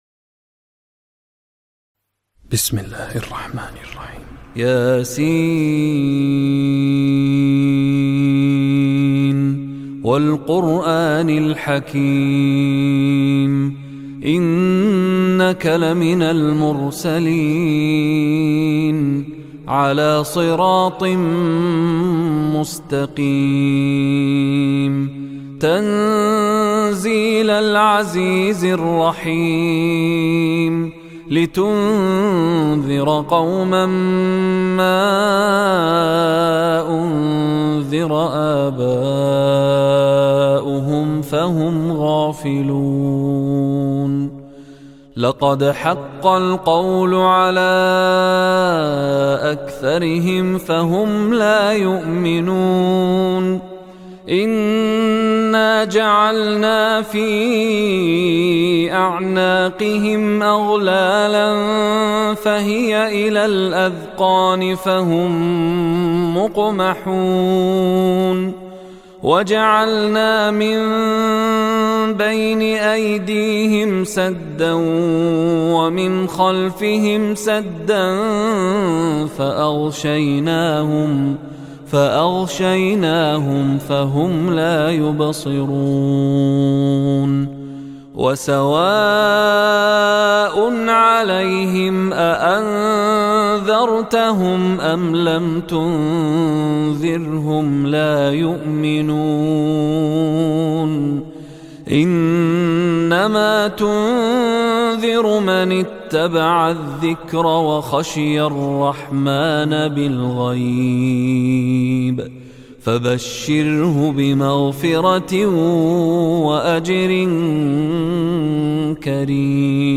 Surah Yasin read online with Arabic text, translation, and MP3 recitation.
suraheyaseen-mishary-rashed-alafasy-listen-online.mp3